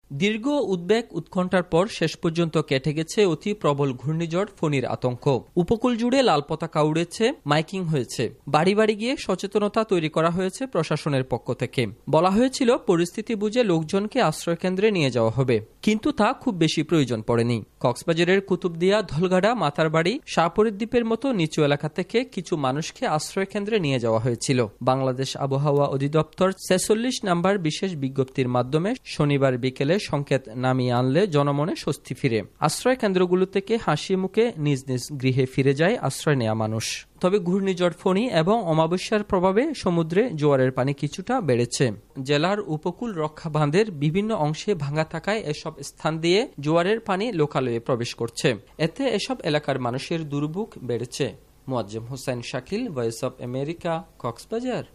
কলকাতা থেকে
রিপোর্ট